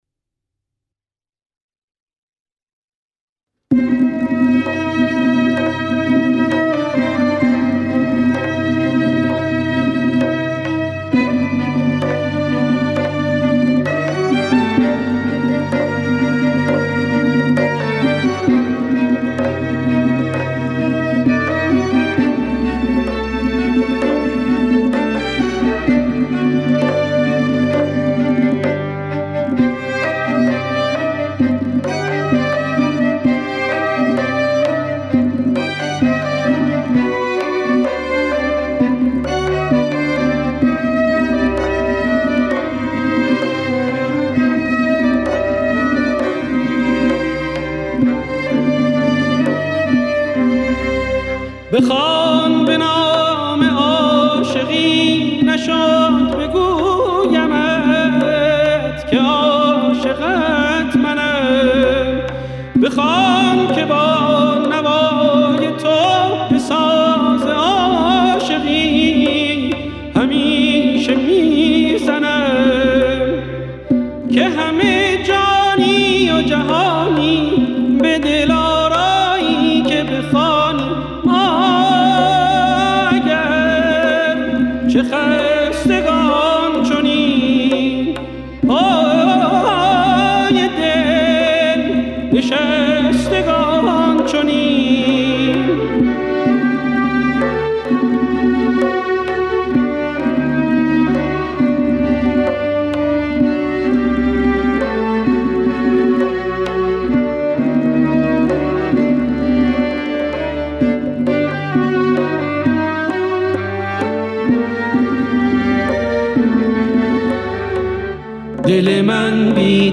تصنیف
در دستگاه همایون اجرا کرده است.
تنبک